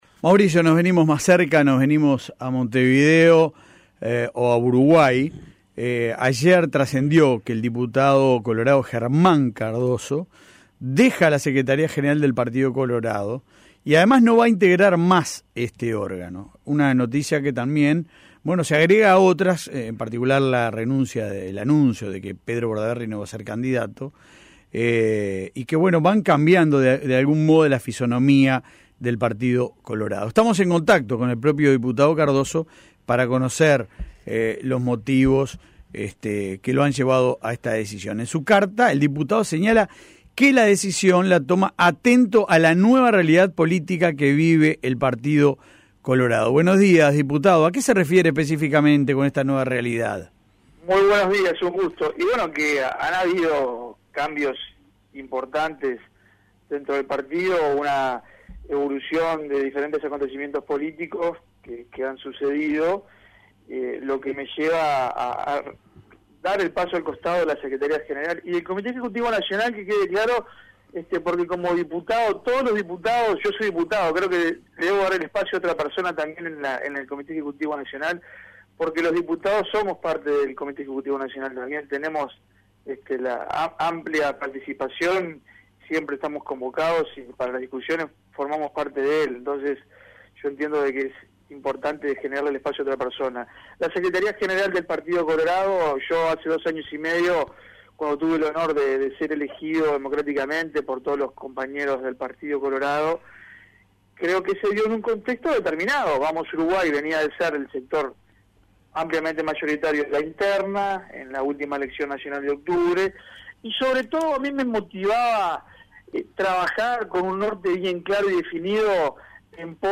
Germán Cardoso en La Mañana
"Con la renuncia de Pedro Bordaberry se adelantan los tiempos políticos" dijo a La Mañana de El Espectador el diputado Germán Cardoso, quien ayer anunció su renuncia al cargo de Secretario General del Partido Colorado.